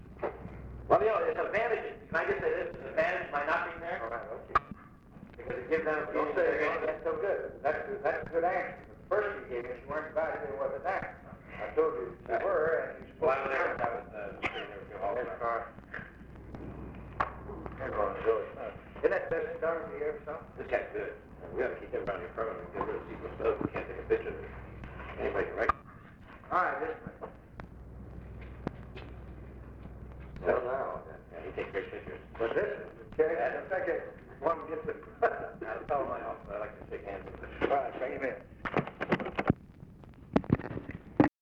OFFICE CONVERSATION, December 10, 1963
Secret White House Tapes | Lyndon B. Johnson Presidency